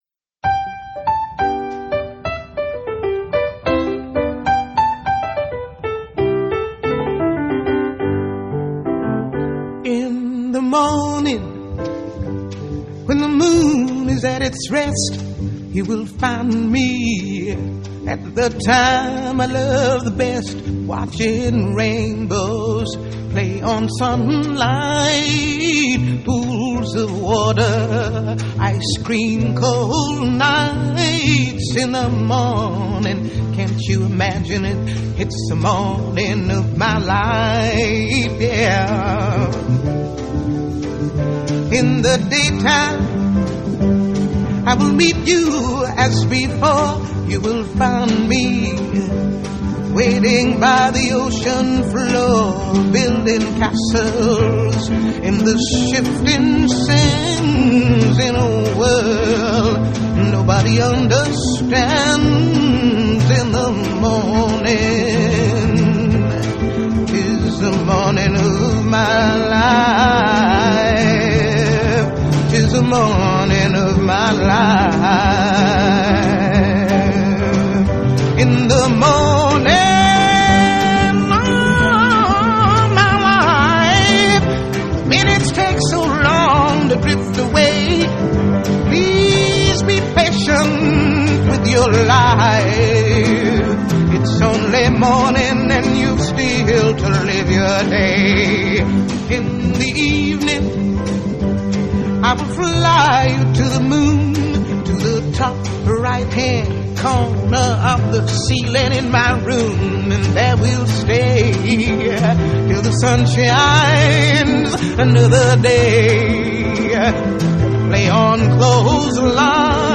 Jazz, Soul, Live